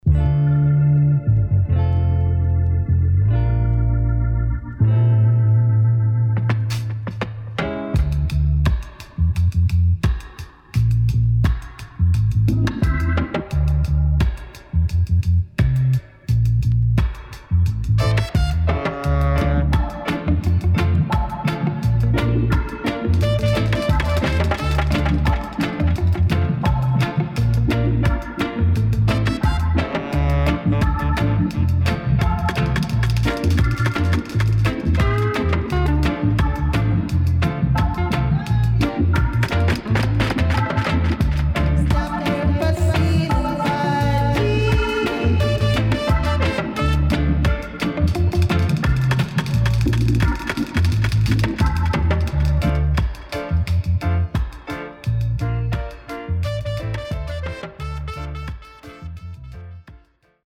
HOME > REISSUE [REGGAE / ROOTS]
UKアーティストの80年Killer Vocal